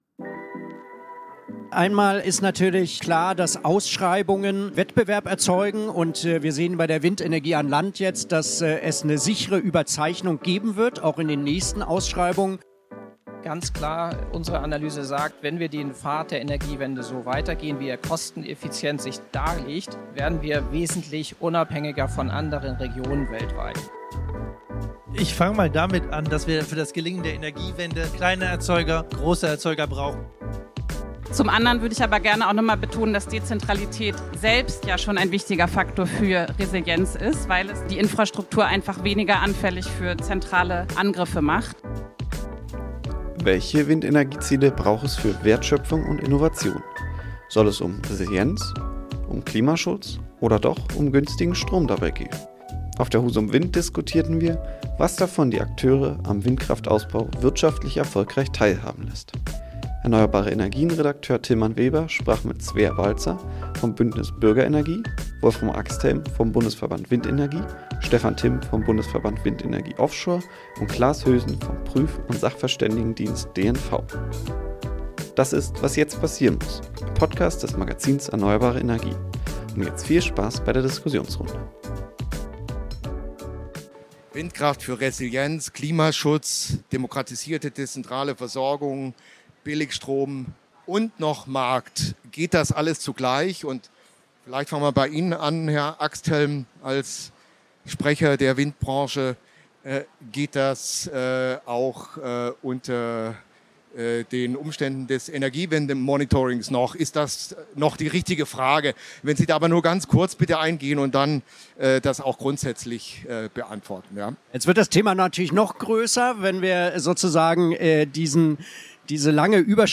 Unsere Gesprächsrunde auf der Husum Wind mit vier Experten nahm unterschiedlichste Blickwinkel auf, aus Sicht von Bürgerwindorganisatoren genauso wie von großen Offshore-Windpark-Investoren. Zur Eröffnung einer Diskurs-Trilogie fragten wir am ersten Messetag in der ersten von drei Talkrunden am Messestand von ERNEUERBARE ENERGIEN, welche Windenergieziele es für unsere Wertschöpfung und Innovation braucht?